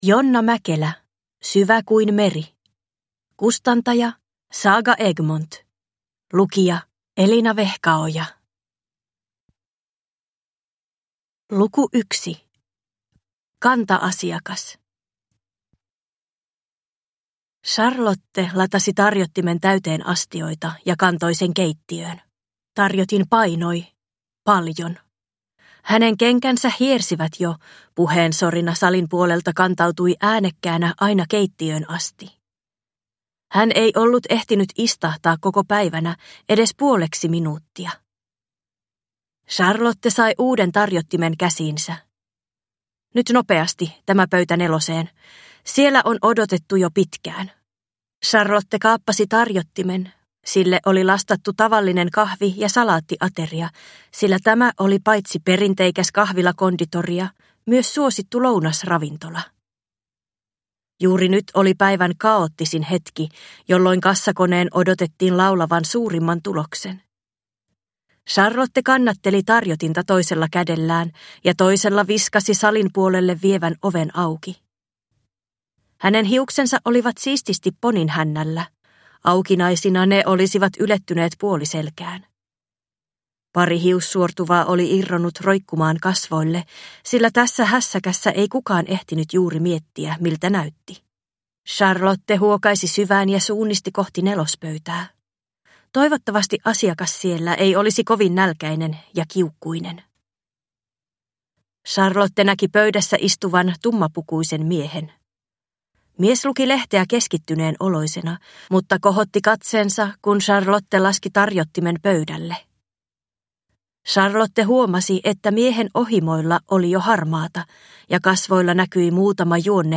Syvä kuin meri (ljudbok) av Jonna Mäkelä